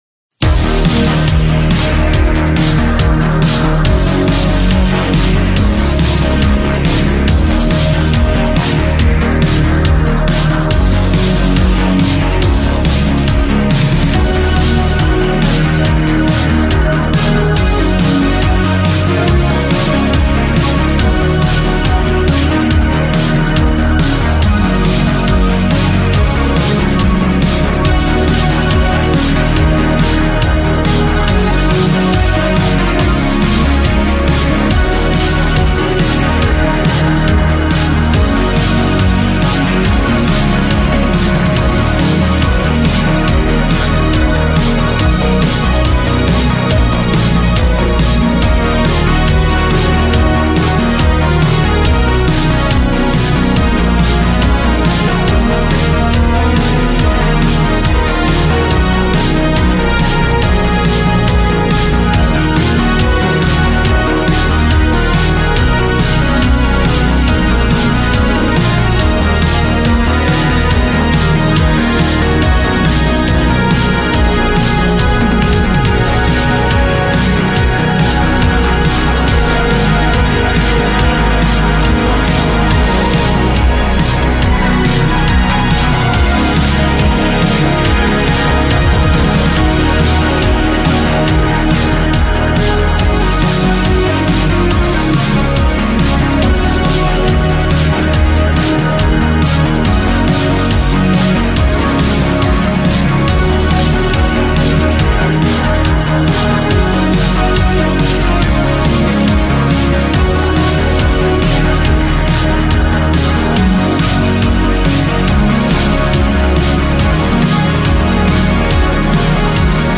new retro wave